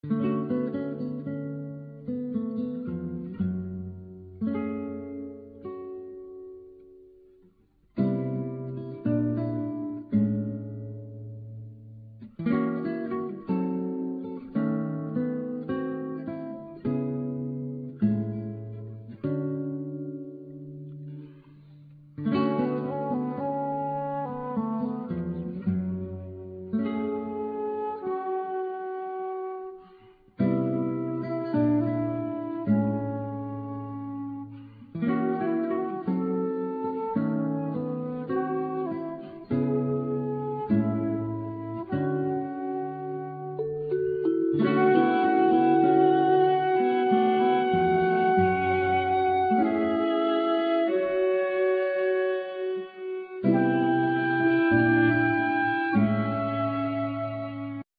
Guitar
Flute
Viola,Violin
Drums,Vibrapone,Cembalo,Piano